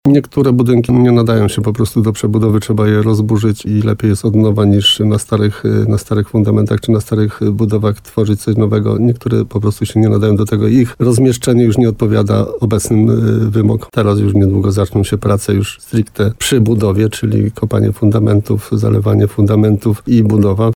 Rozbiórka i oczyszczenie placu jest konieczne, żeby mogła ruszyć budowa nowej siedziby dla tej placówki medycznej – mówi wójt Jan Kotarba.